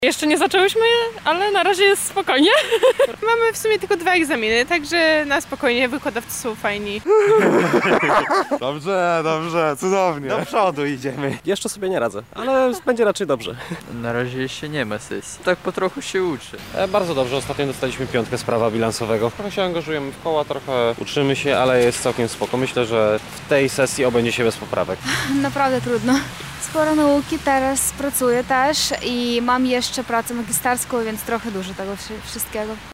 Zapytaliśmy lubelskich studentów, jakie mają nastroje przed zbliżającymi się egzaminami:
SONDA